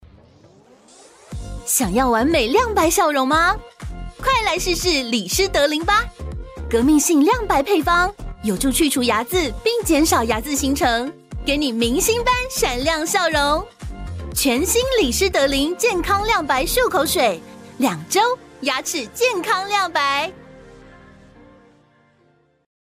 國語配音 女性配音員
她擅長國語配音，聲線既能俏皮活潑，也能沉靜溫柔，特別擅長詮釋少年少女角色與富有童趣的動畫情節。
• 聲線清新、富有生命力，擁有強烈親和力，適合各類動畫、遊戲角色